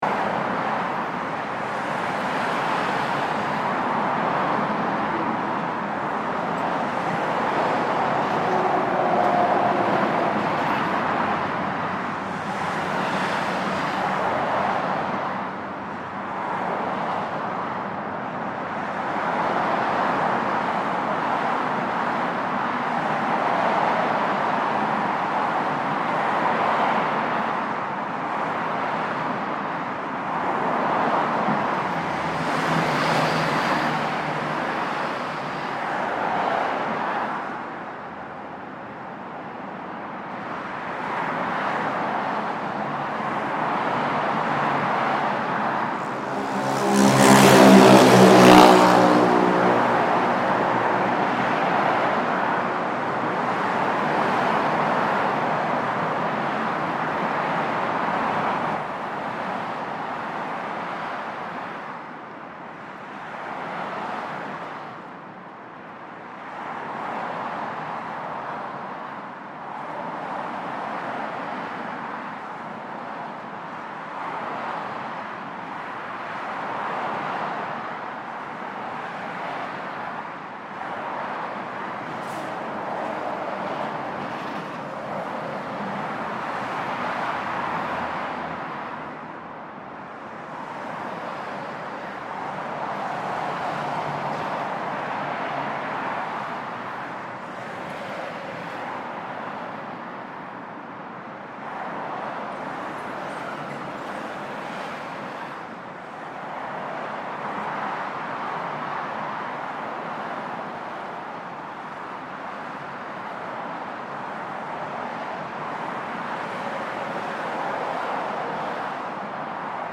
Traffic is constant. This recording of the Gangbyeon Expressway was made underneath its eastbound lanes where it nips under the Seogang-daegyo (bridge).